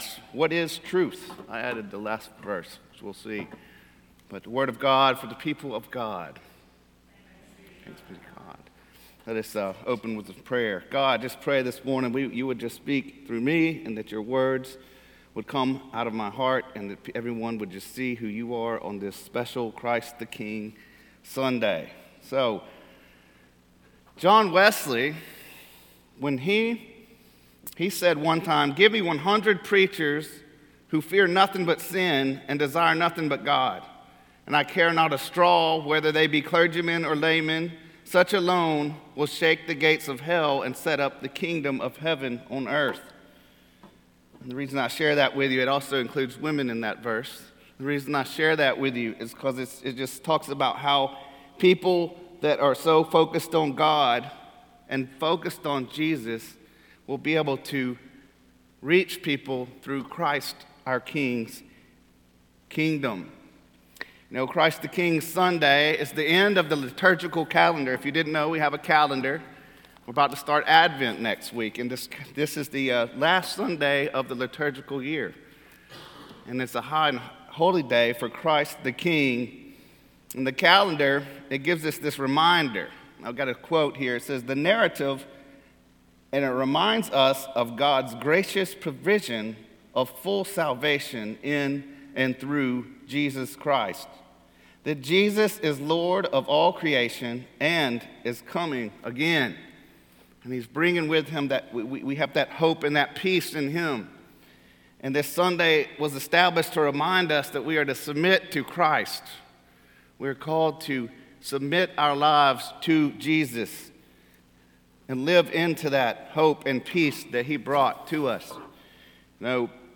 Sermons - Cokesbury Church